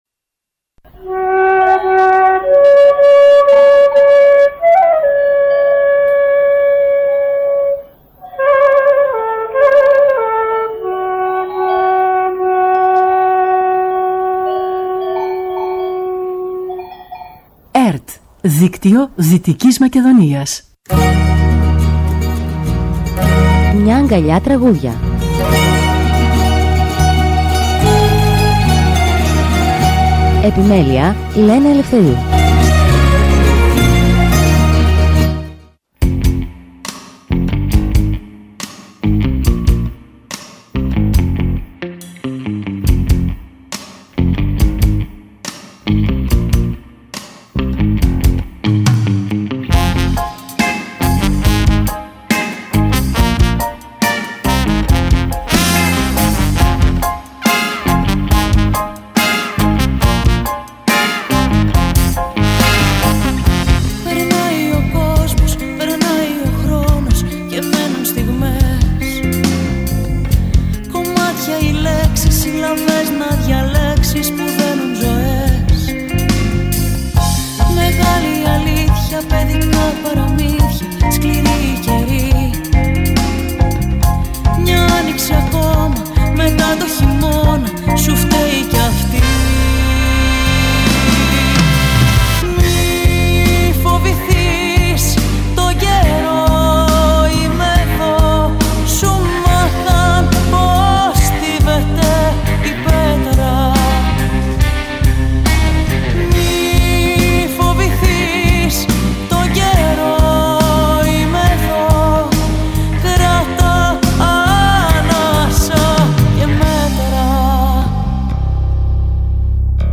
Μουσική εκπομπή που παρουσιάζει νέες δισκογραφικές δουλειές, βιβλιοπαρουσιάσεις και καλλιτεχνικές εκδηλώσεις.
ΣΥΝΕΝΤΕΥΞΗ